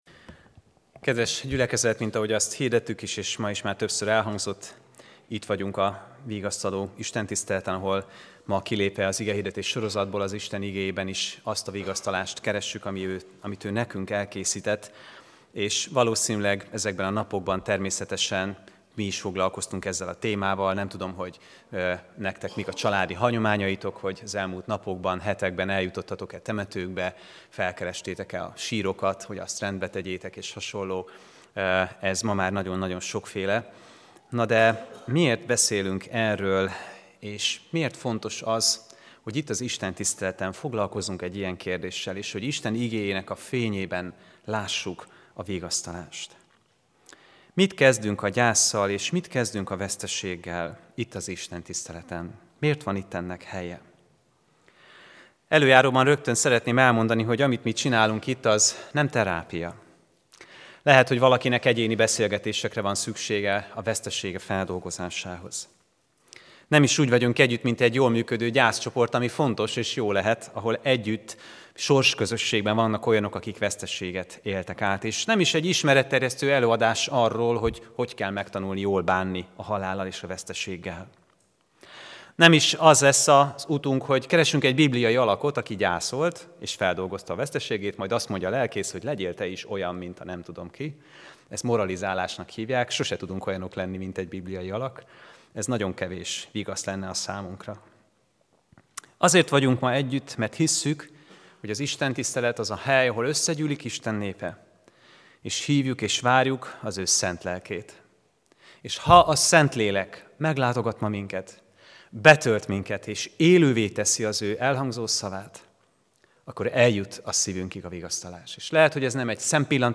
AZ IGEHIRDETÉS LETÖLTÉSE PDF FÁJLKÉNT AZ IGEHIRDETÉS MEGHALLGATÁSA
Vigasztaló istentisztelet